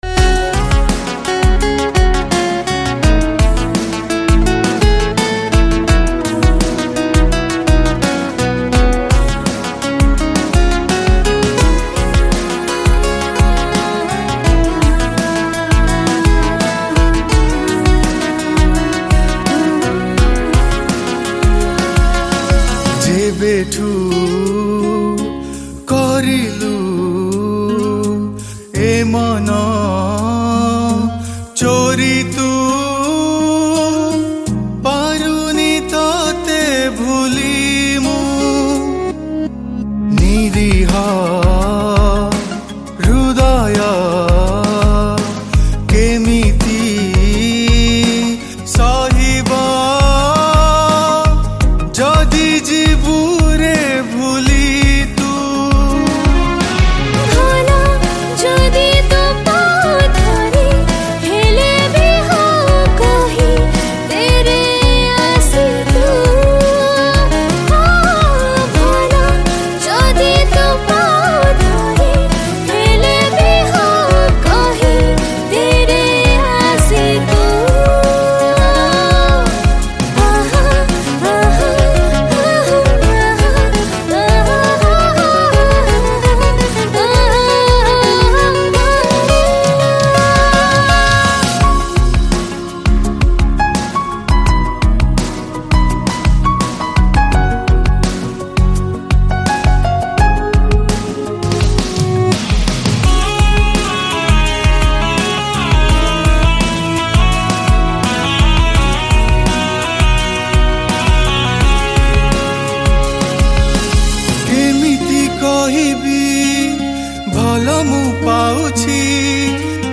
Odia New Romantic Song